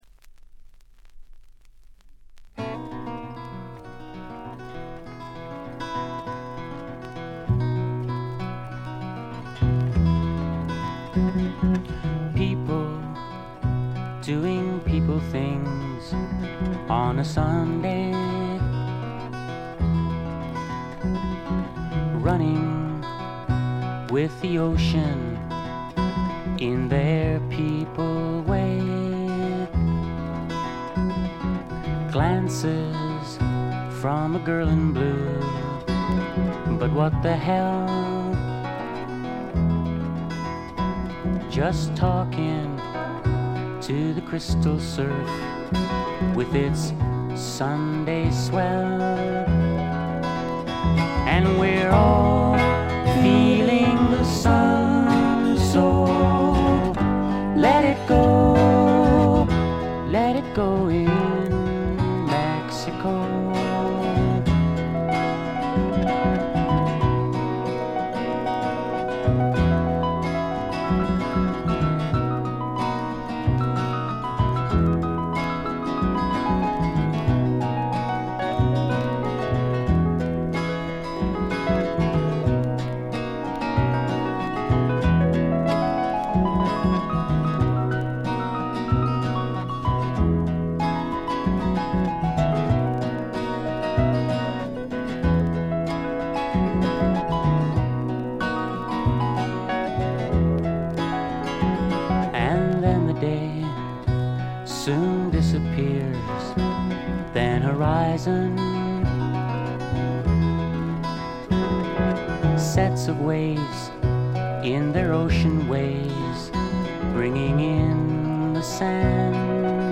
ところどころでチリプチ（特にA1冒頭、A3冒頭あたり）。
ドラムレスで、Heron的な木漏れ日フォークのほんわか感と、米国製メロー・フォーク的なまろやかさが同居した名作です。
試聴曲は現品からの取り込み音源です。